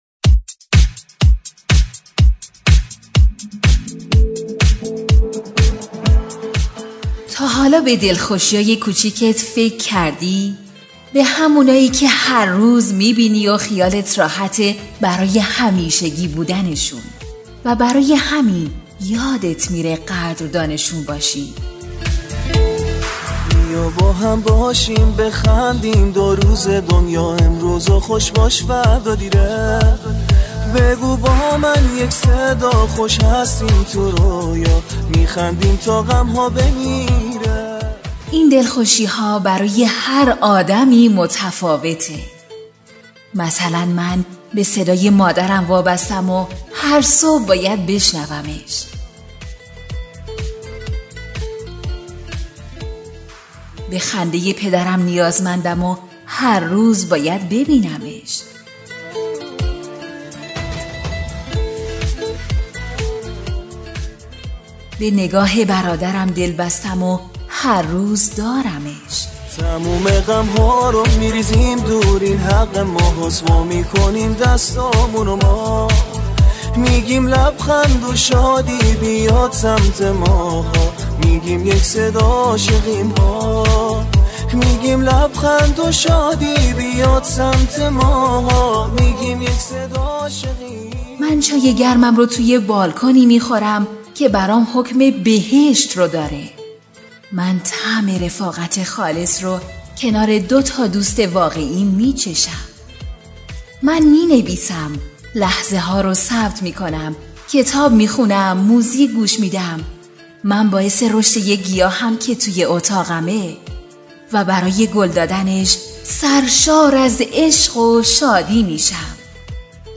دکلمه خرسندم